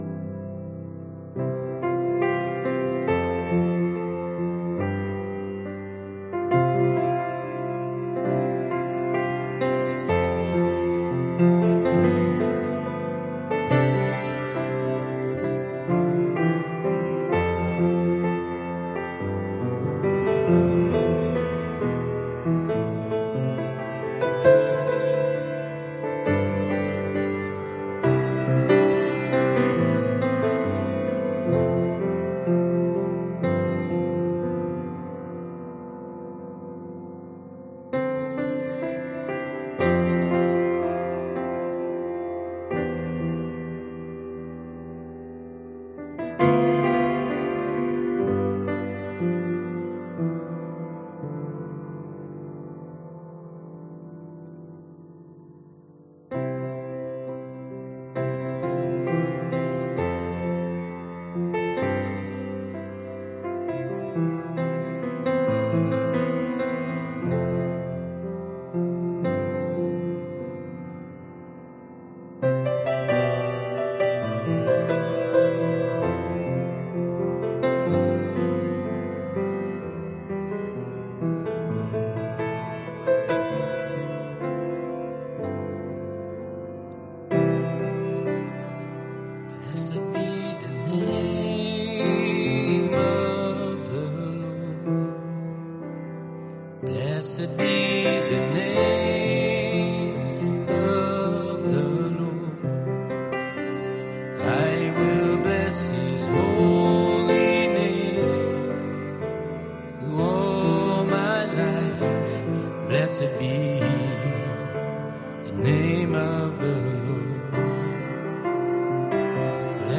Soaking Prayer and Worship 9-9-2025 audio only